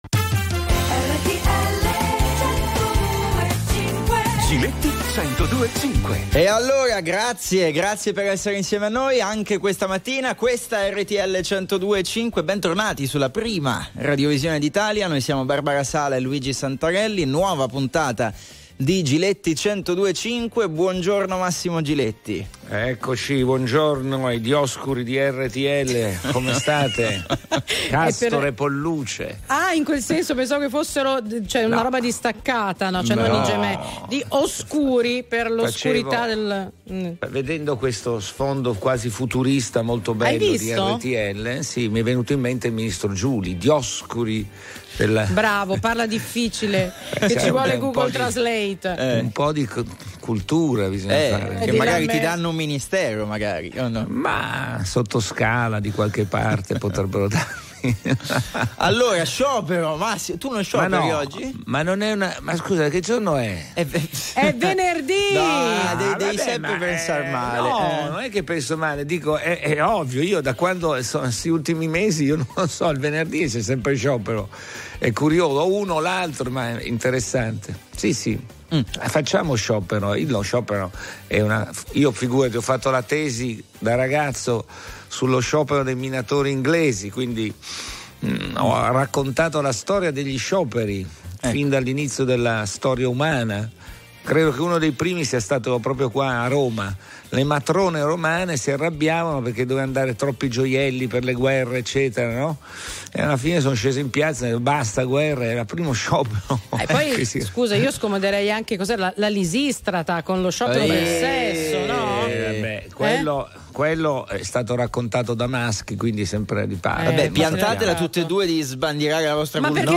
Telefoni aperti ai Very Normal People sui fatti della settimana. Il tema di oggi è se quello di oggi è uno sciopero politico?